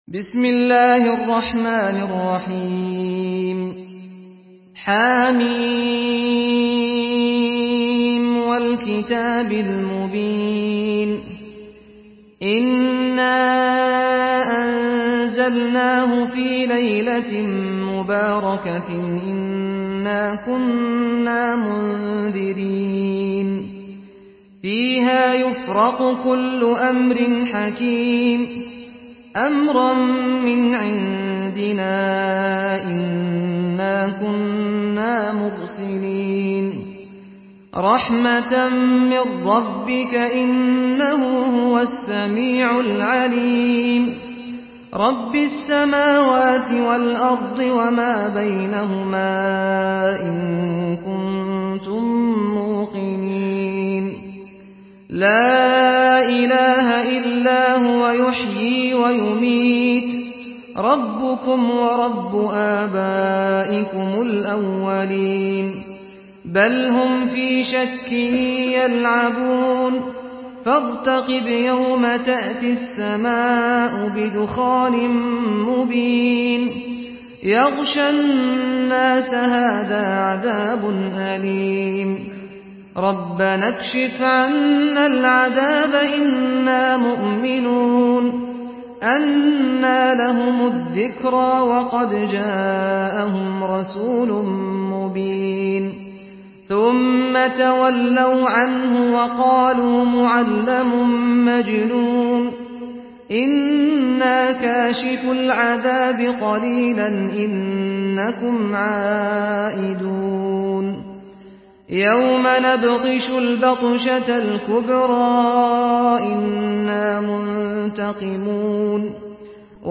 تحميل سورة الدخان بصوت سعد الغامدي
مرتل حفص عن عاصم